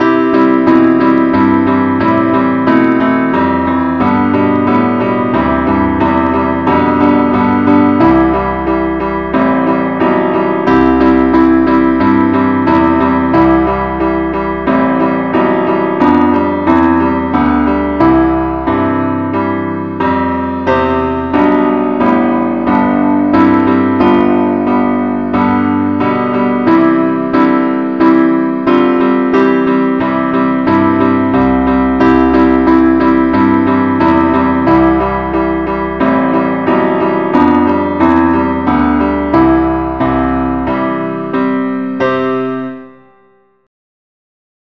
henley-keyboardonly-keyc.mp3